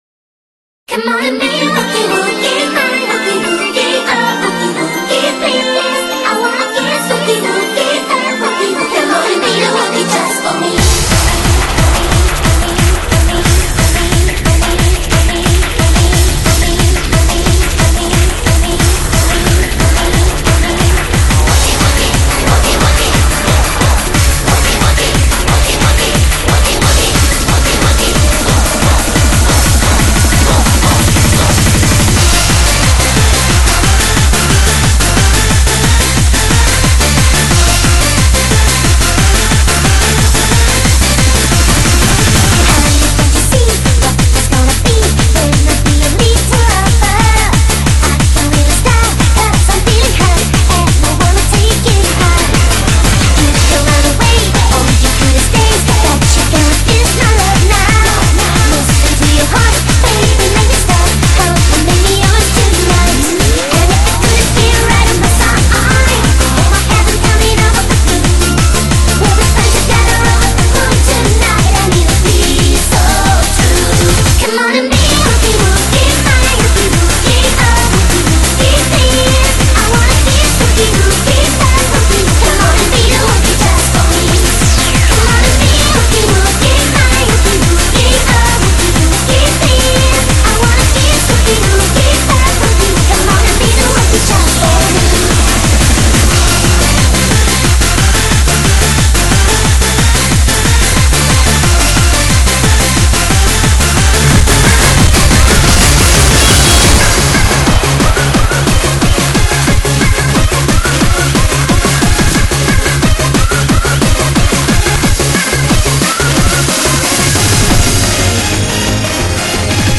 BPM170-180
Audio QualityPerfect (High Quality)
awesome mix of speed pop tracks